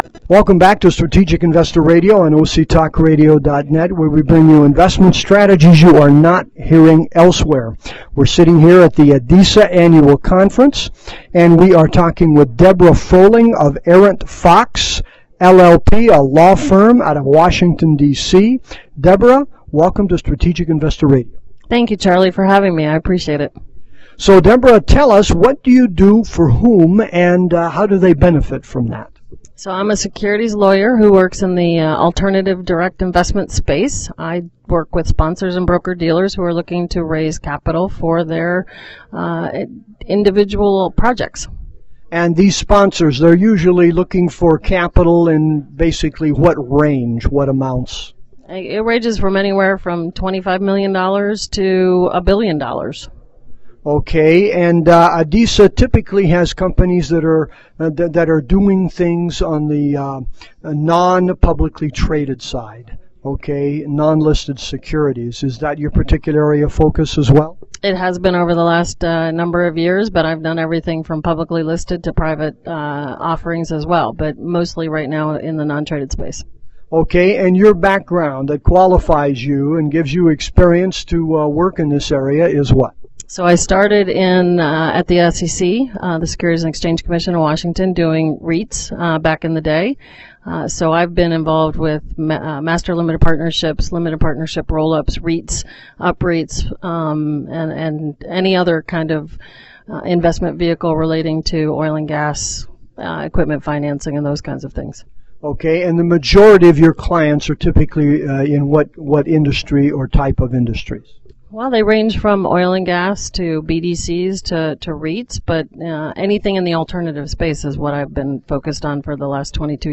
This interview was taken at the annual ADISA conference.